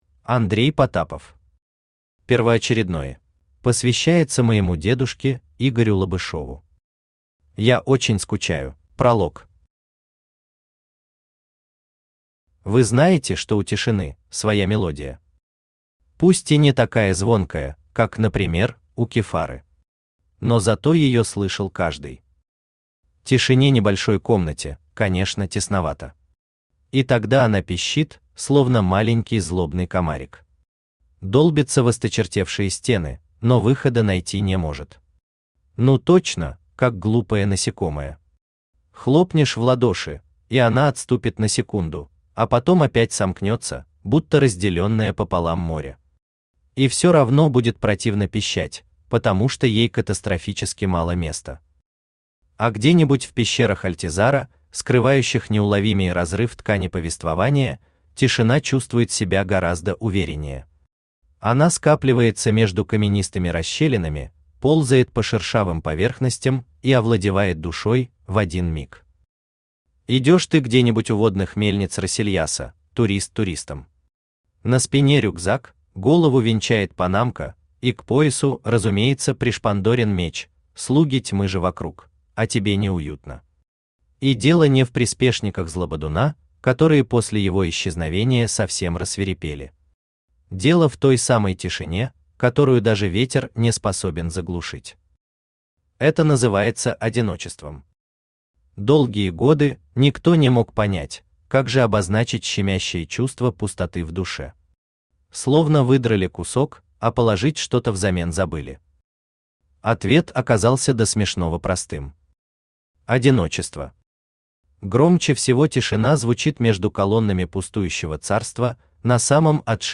Aудиокнига Первоочередной Автор Андрей Потапов Читает аудиокнигу Авточтец ЛитРес.